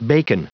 Prononciation du mot bacon en anglais (fichier audio)
Prononciation du mot : bacon